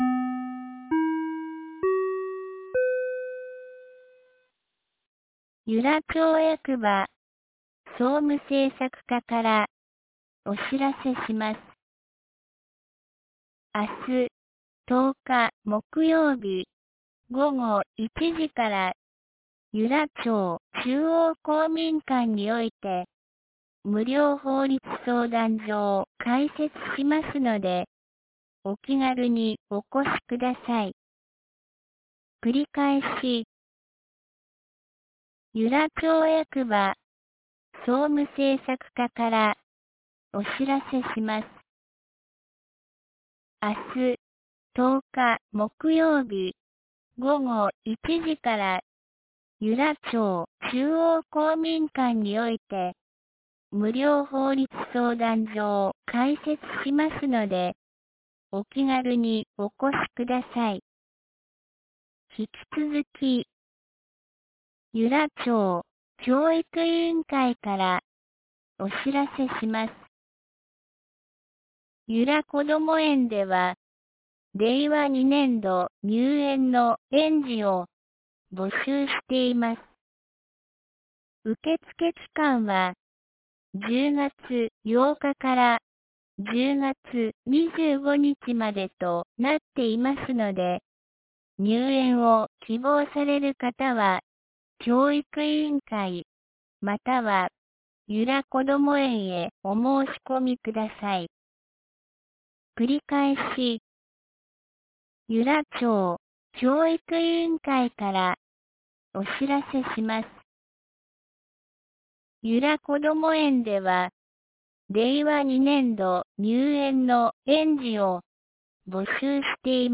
2019年10月09日 17時12分に、由良町から全地区へ放送がありました。
放送音声